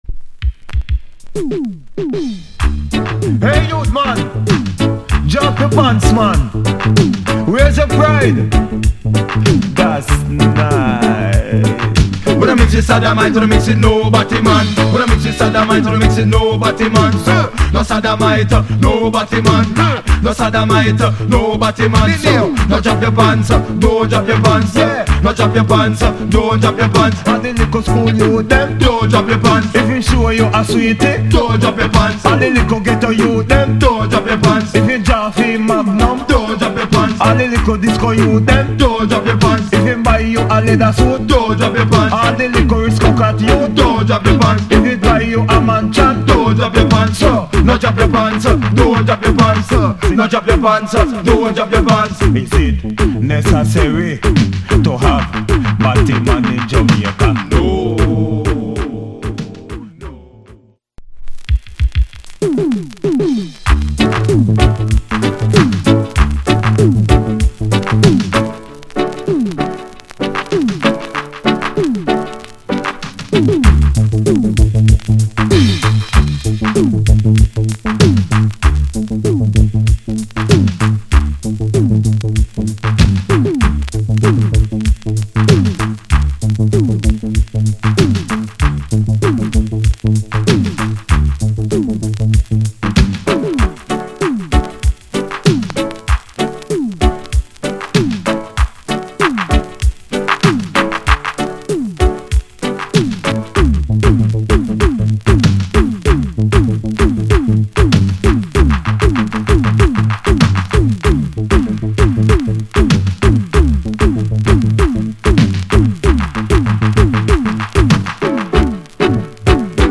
Genre Reggae80sLate / Male DJ